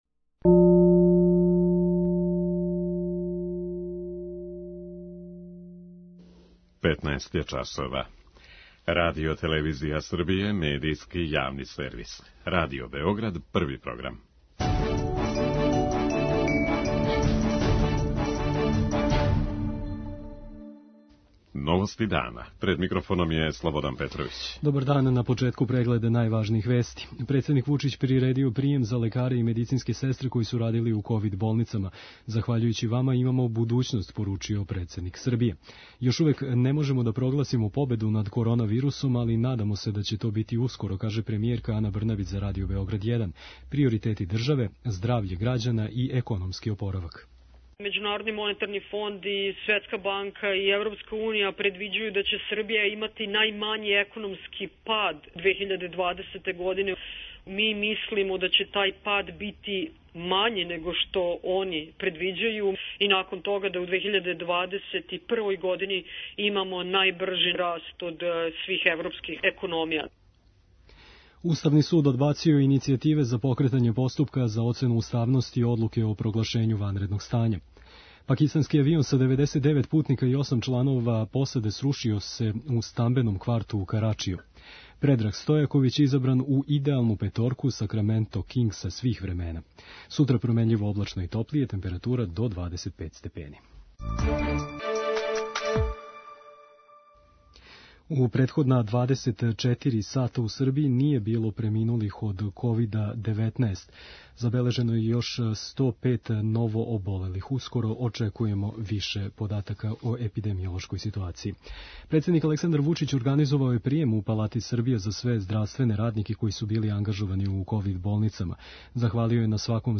Srbija još uvek ne može da proglasi pobedu nad korona virusom, ali nadamo se da će to biti uskoro, izjavila je za Prvi program Radio Beograda premijerka Srbije Ana Brnabić.